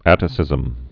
(ătĭ-sĭzəm)